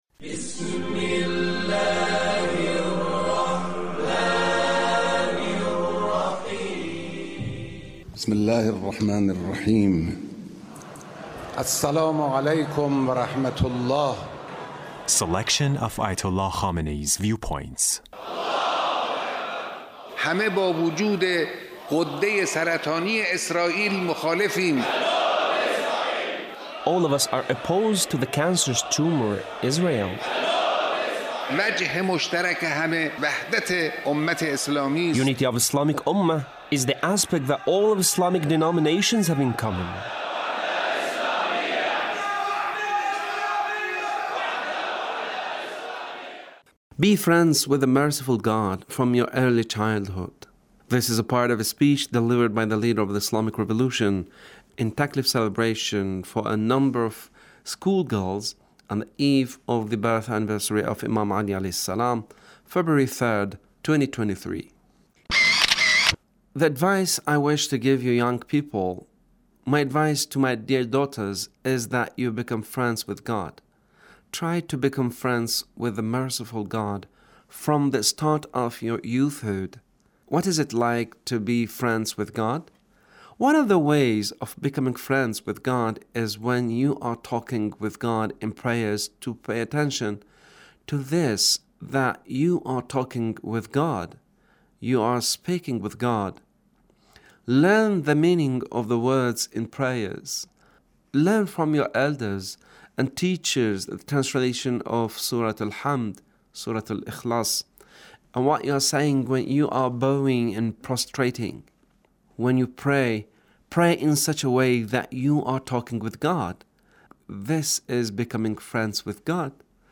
Leader's Speech Taklif Celebration